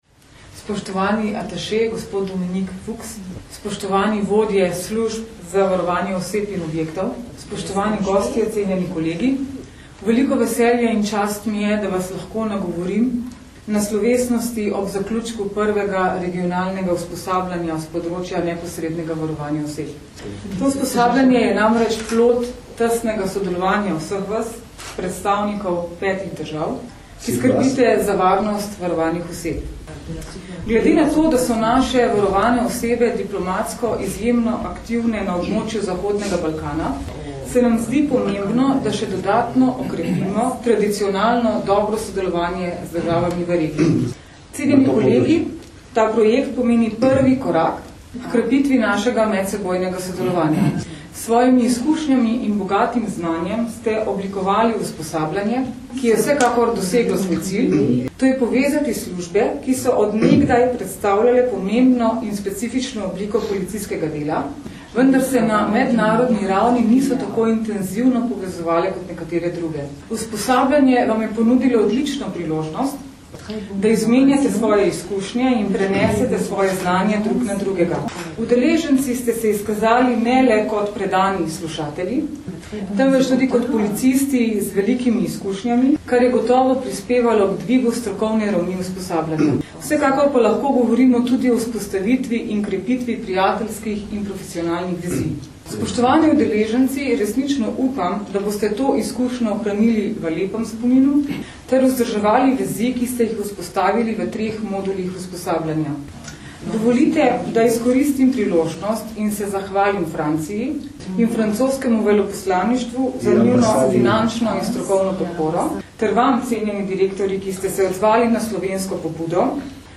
Zvočni posnetek izjave mag. Tatjane Bobnar (mp3)